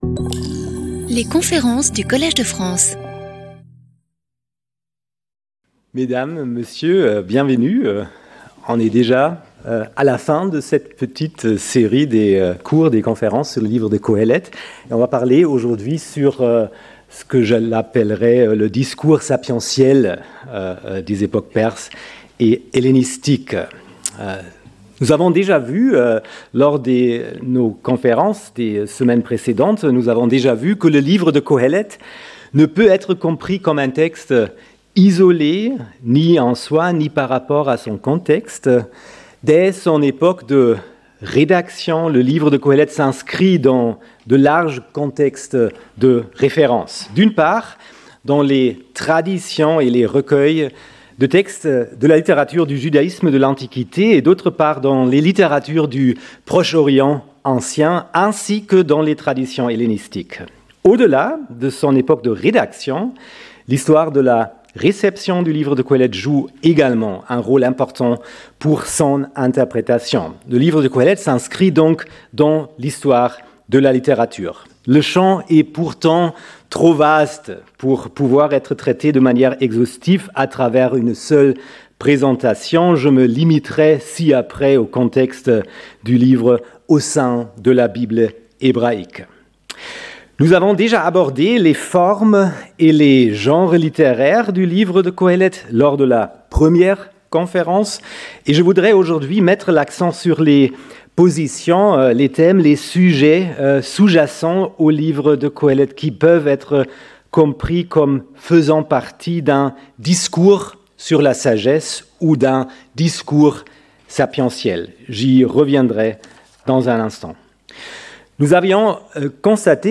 Conférencier invité